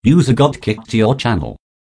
user.joined.kicked.wav